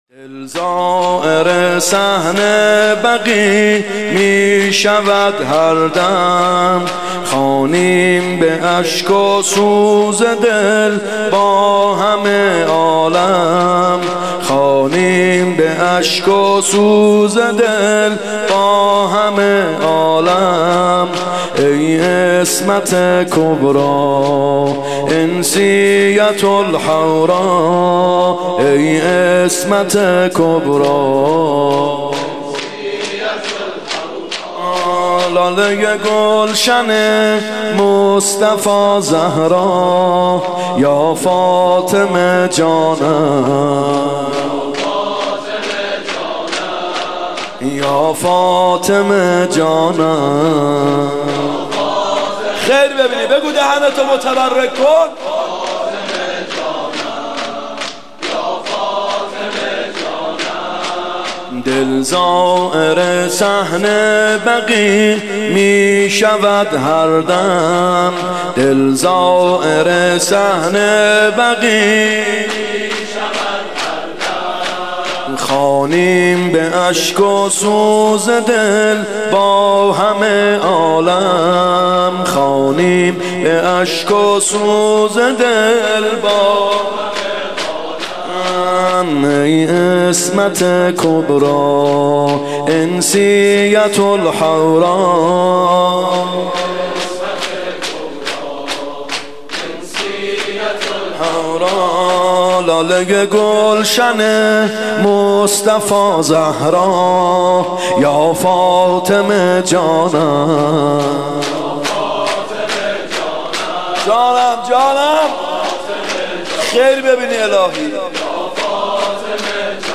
فاطمیه اول 95 شور سرود پایانی ( دل زائر صحن بقیع میشوم هر دم
فاطمیه اول هیئت یامهدی (عج)